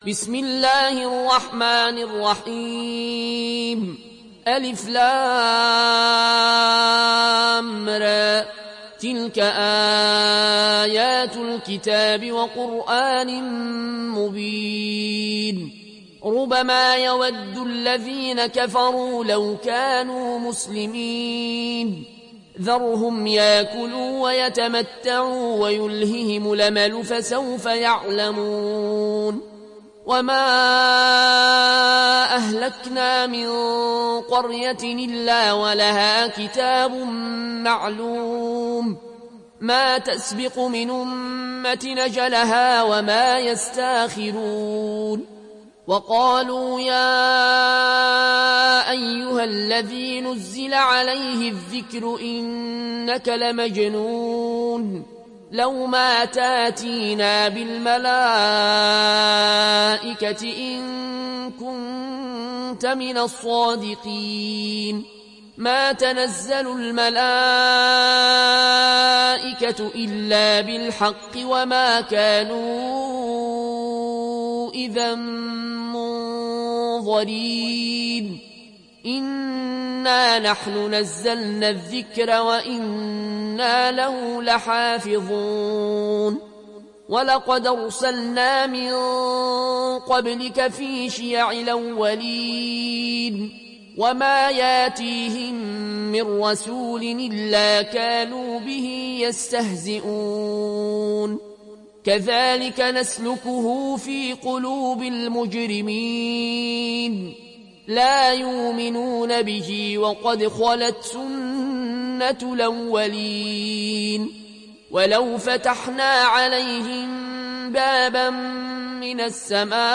Riwayat Warsh